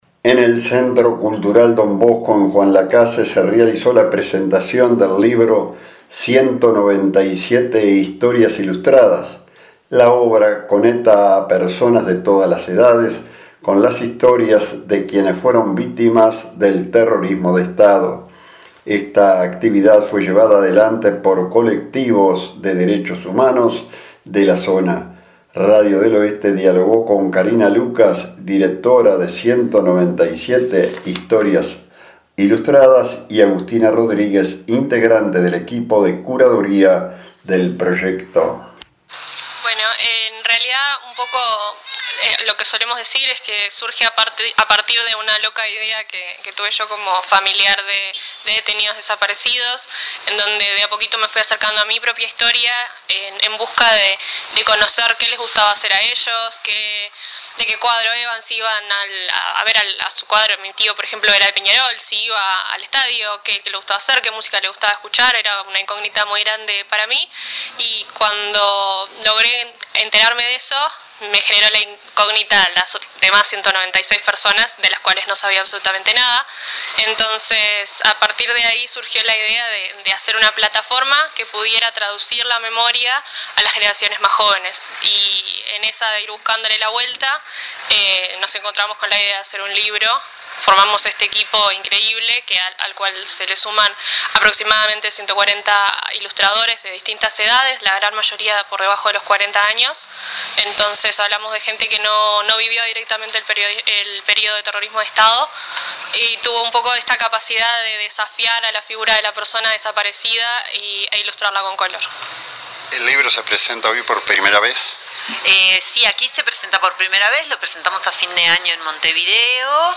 En el Centro Cultural Don Bosco en Juan Lacaze se realizó la presentación del libro «197 historias ilustradas » la obra conecta a personas de todas las edades con las historias de quienes fueron víctimas del terrorismo de estado.